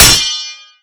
blade1hand_parry_001.wav